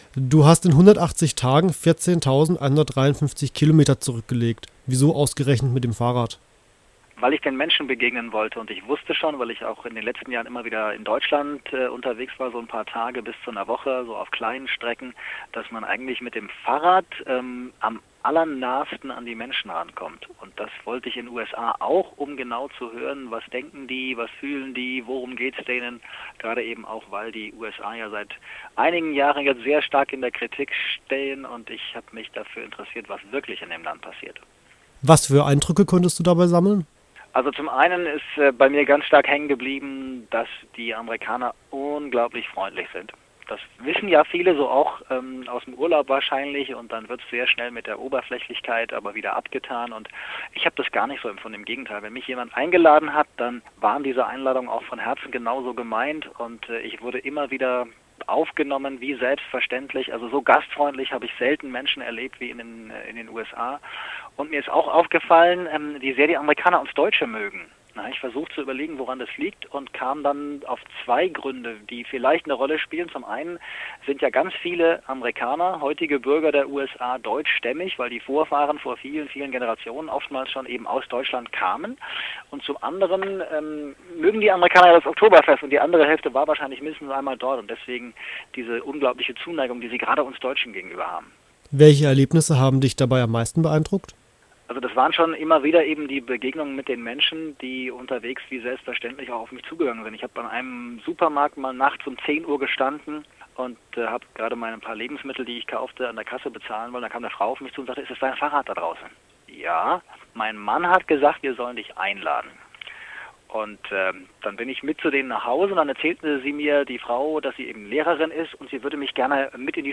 Americana – Interview